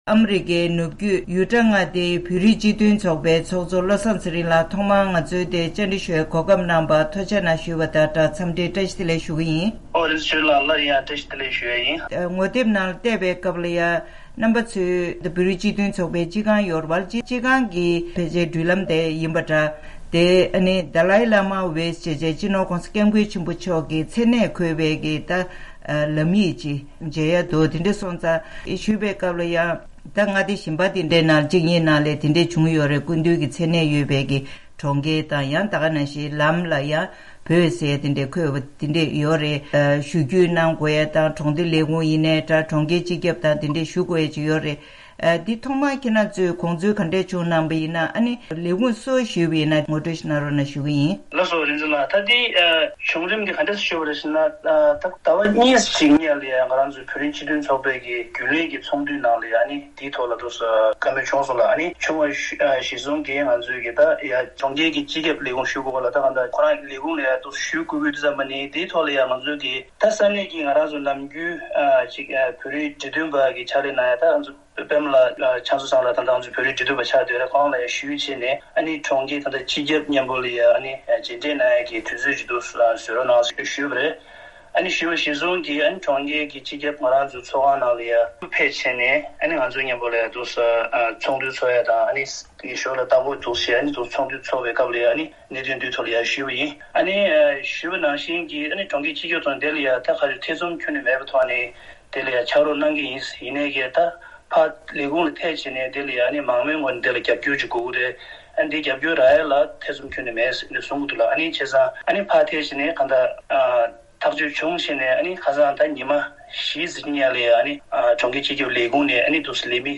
དམིགས་བསལ་གནས་འདྲིའི་ལེ་ཚན་ནང་། ཨ་རི་ནུབ་བརྒྱུད་ཡུ་ཊ་མངའ་སྡེའི་གྲོང་ཁྱེར་སོལ་ལེཀ་གི་བོད་མིའི་སྤྱི་མཐུན་ཚོགས་པའི་ཚོགས་ཁང་གི་འགྲུལ་ལམ་སྟེང་།